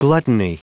Prononciation du mot gluttony en anglais (fichier audio)
gluttony.wav